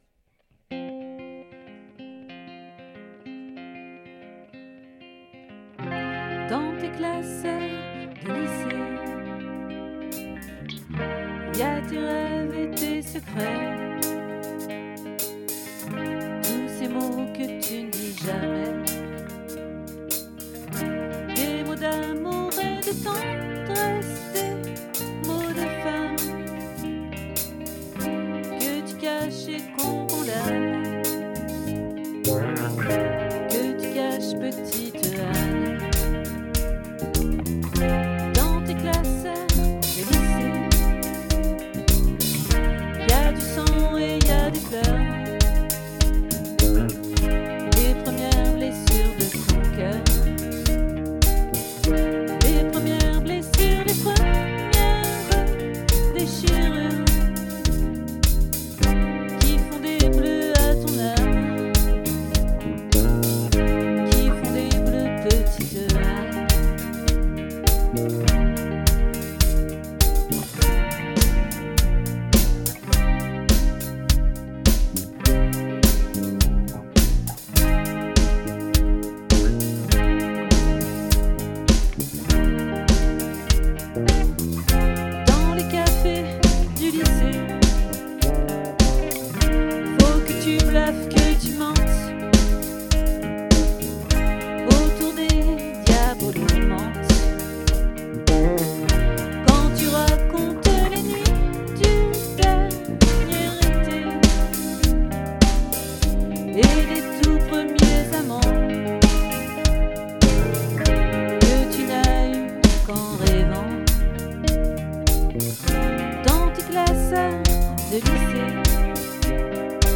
🏠 Accueil Repetitions Records_2022_06_08